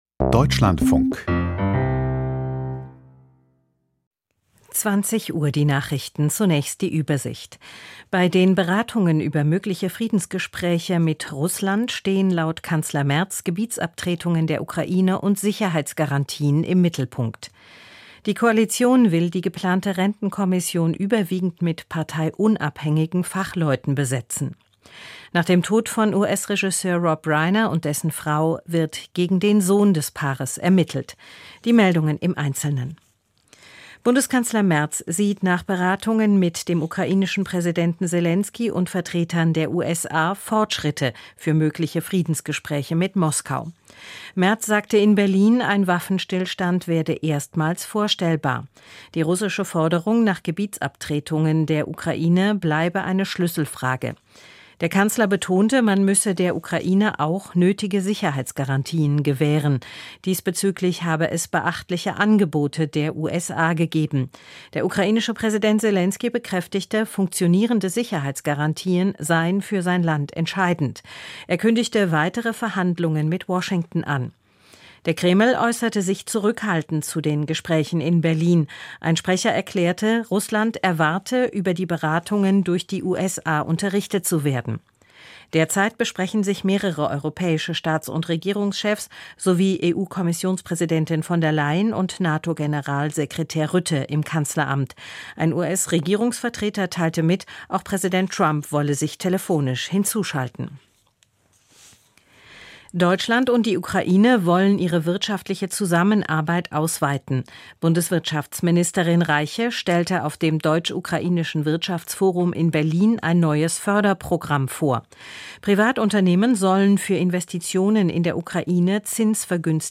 Die Nachrichten vom 15.12.2025, 20:00 Uhr
Aus der Deutschlandfunk-Nachrichtenredaktion.